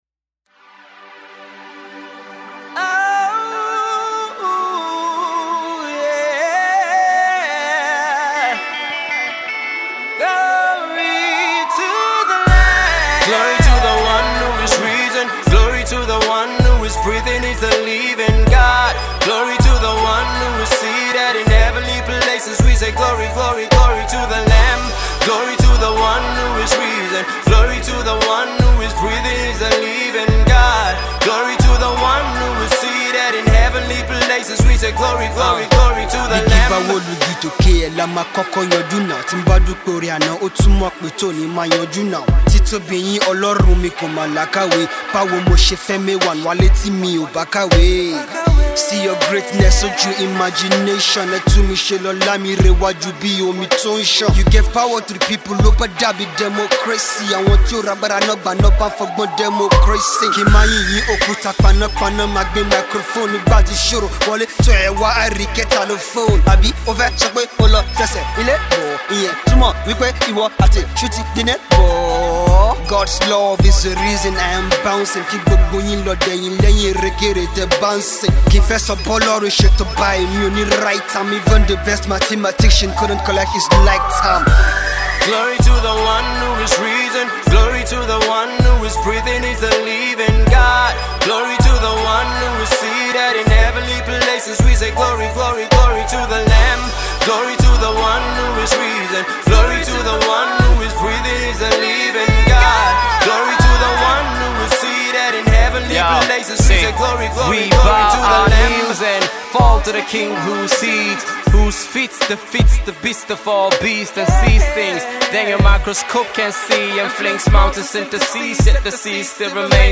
Urban gospel